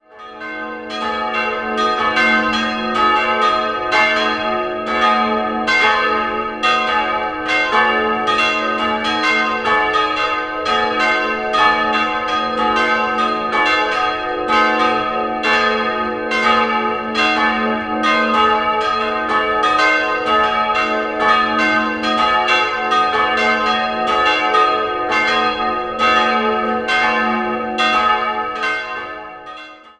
Die Kirche am Ortsrand stammt im Kern noch aus romanischer Zeit, wurde aber im 17. Jahrhundert neu errichtet. Die Altarausstattung ist der Neuromanik zuzuordnen. 3-stimmiges Geläut: a'-c''-es'' Die beiden kleineren Glocken wurden 1889 von Max Gugg, die große 1948 von Anton Gugg, jeweils in Straubing, gegossen.